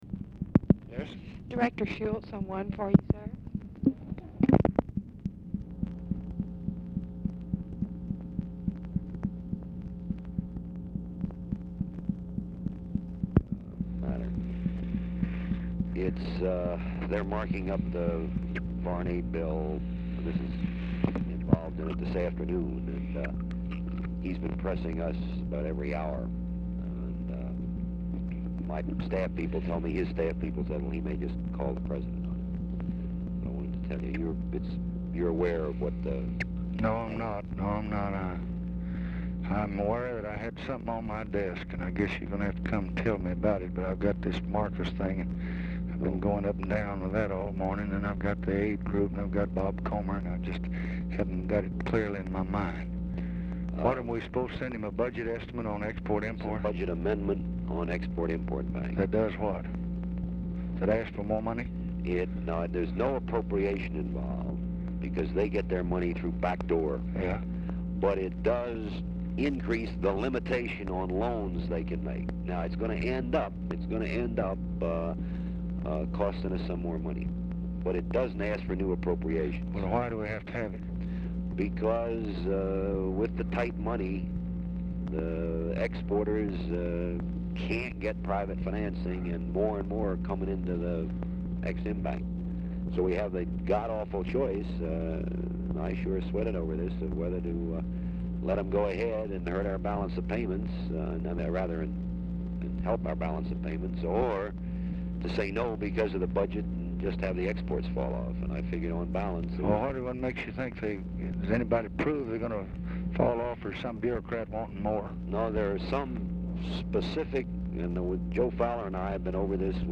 Telephone conversation # 10772, sound recording, LBJ and CHARLES SCHULTZE
RECORDING STARTS AFTER CONVERSATION HAS BEGUN
Format Dictation belt
Location Of Speaker 1 Oval Office or unknown location